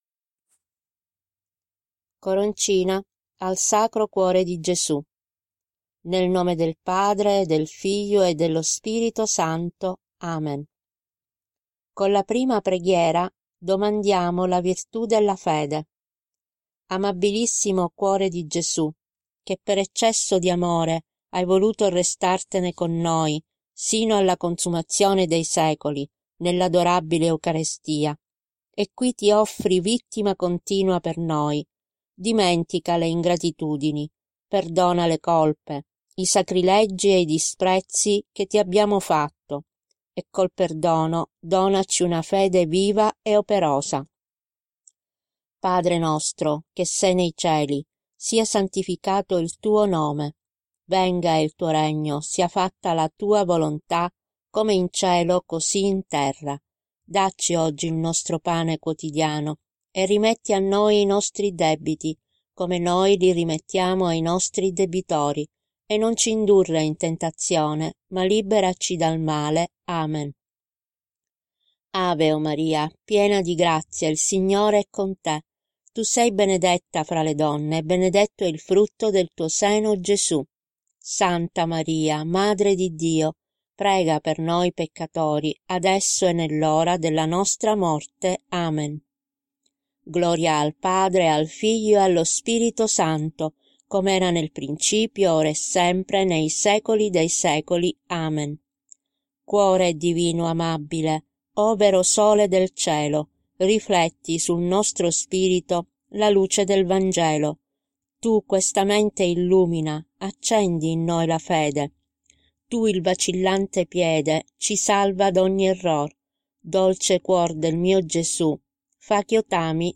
Coroncina al Cuore di Gesù, con voce guida (audiomp3) da recitare per tutto il mese di Giugno.